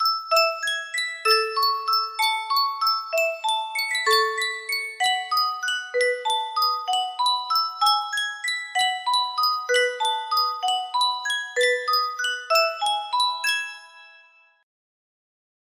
Sankyo Music Box - On Top of Old Smokey 7X music box melody
Full range 60